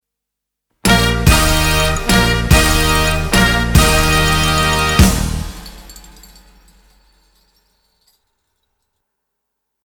Tusch 3x.mp3